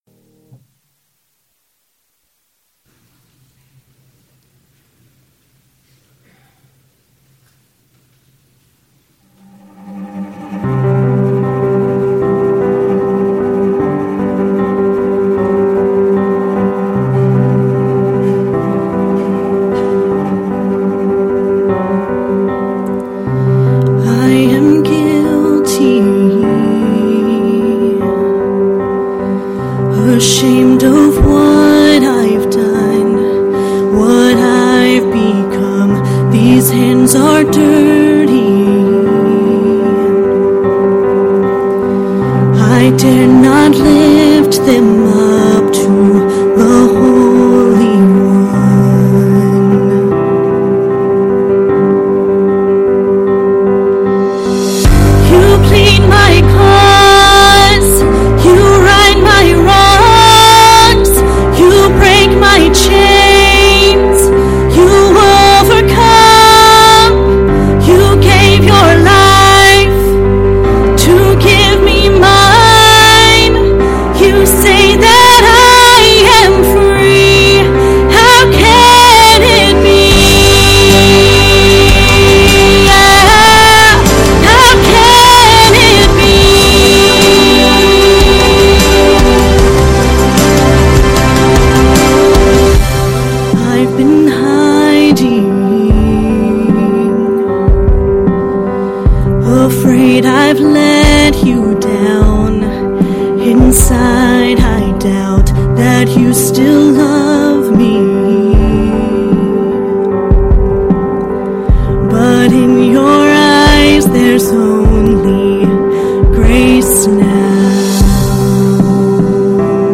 Sunday Sermons - First Baptist Church of Buda - Page 28